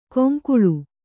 ♪ koŋkuḷi\u